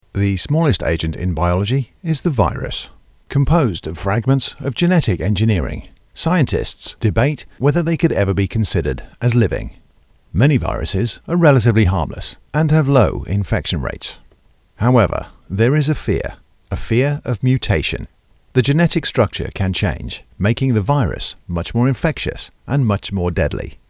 Male
English (British)
Adult (30-50), Older Sound (50+)
Medical Narrations
4: Medical Voice Overs
All our voice actors have professional broadcast quality recording studios.
0714Medical_Voice_Over.mp3